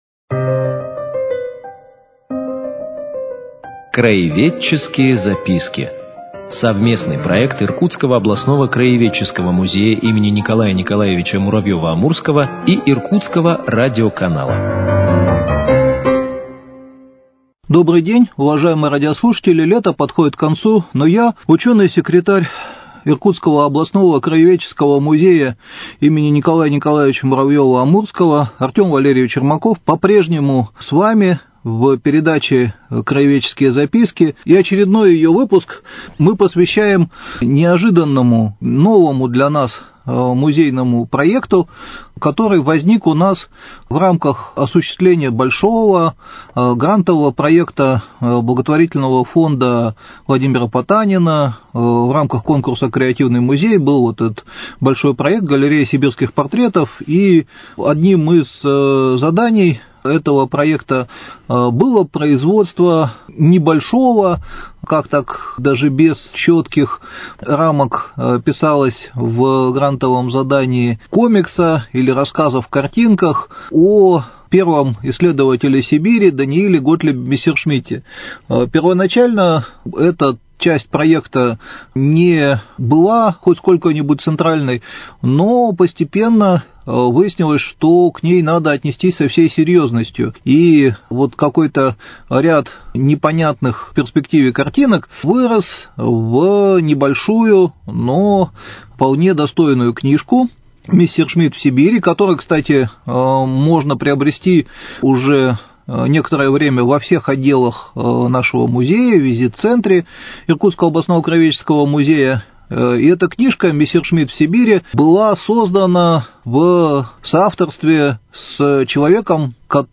Краеведческие записки: Беседа